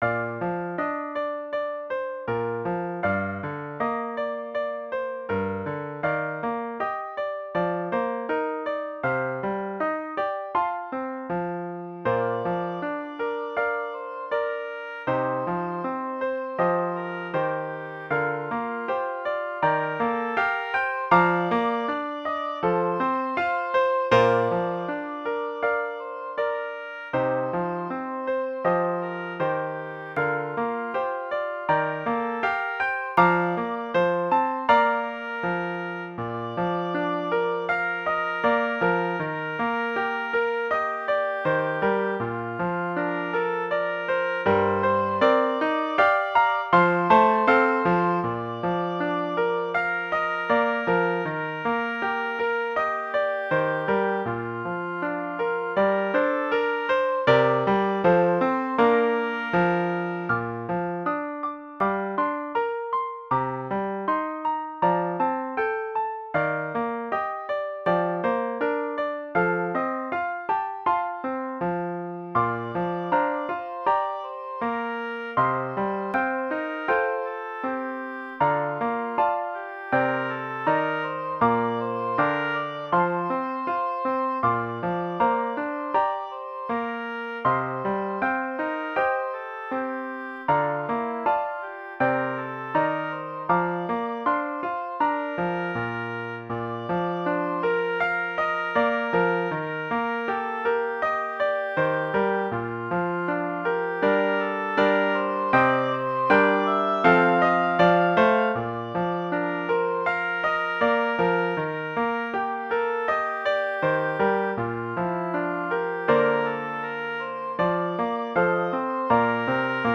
Intermediate Instrumental Solo with Piano Accompaniment.
Christian, Gospel, Sacred.
a gentle, meditative mood